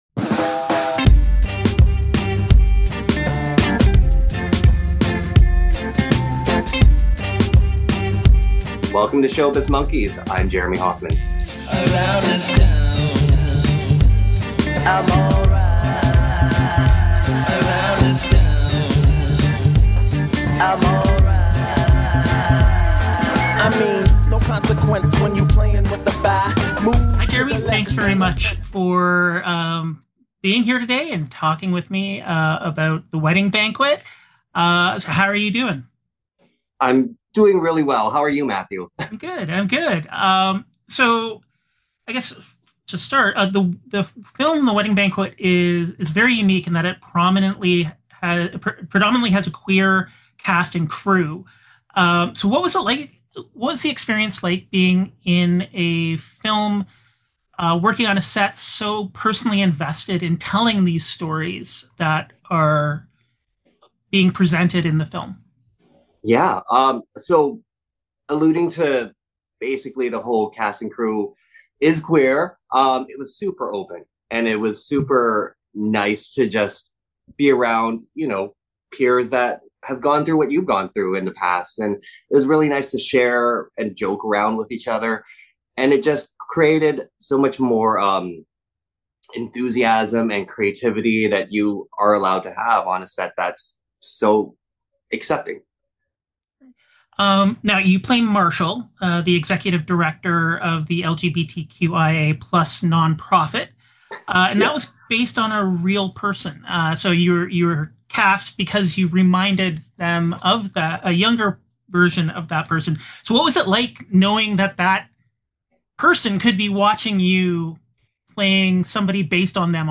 Interview
I got the chance to connect over Zoom